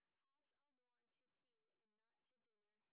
sp14_street_snr20.wav